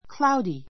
cloudy 小 A1 kláudi ク ら ウディ 形容詞 比較級 cloudier kláudiə r ク ら ウディア more cloudy 最上級 cloudiest kláudiist ク ら ウディエ スト most cloudy 曇 くも りの , 曇った a cloudy sky a cloudy sky 曇り空 It is cloudy today.